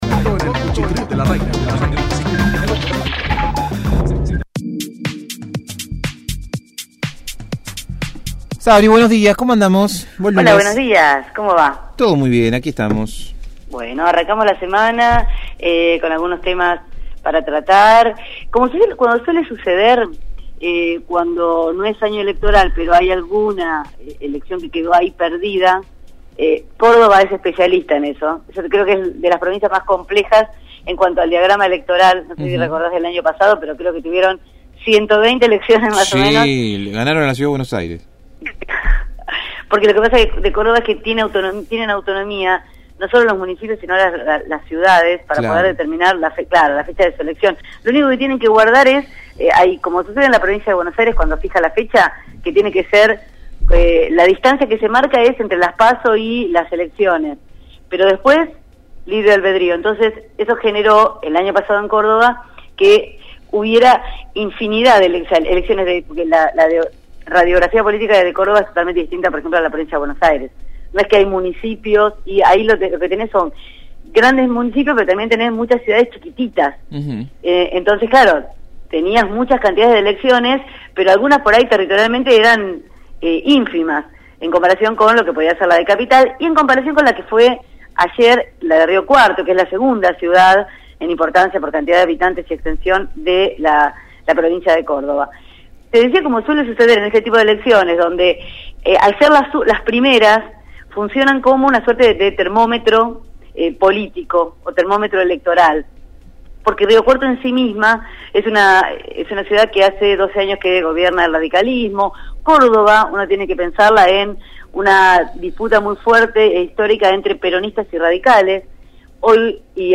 realizó su habitual informe sobre la actualidad política.